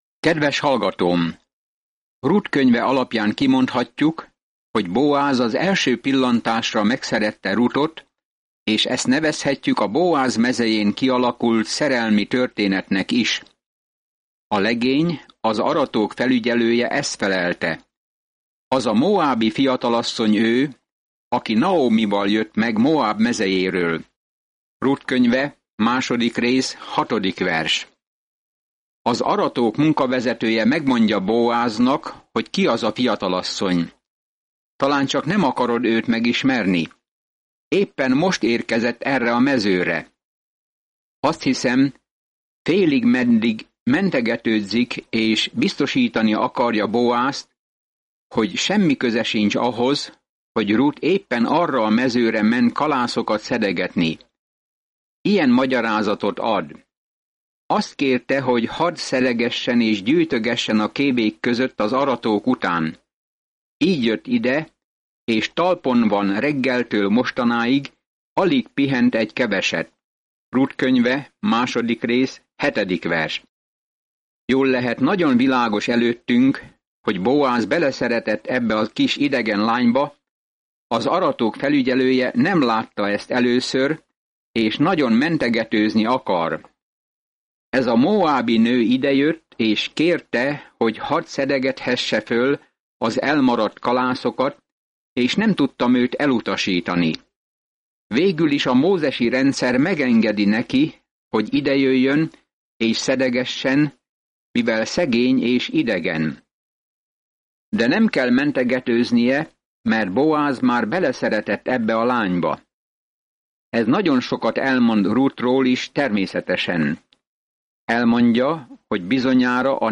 Szentírás Ruth 2:6-16 Nap 4 Olvasóterv elkezdése Nap 6 A tervről Ruth, egy szerelmi történet, amely Isten irántunk való szeretetét tükrözi, egy hosszú történelemszemléletet ír le – beleértve Dávid királyét… és még Jézus történetét is. Napi utazás Ruthban, miközben hallgatod a hangos tanulmányt, és olvasol válogatott verseket Isten szavából.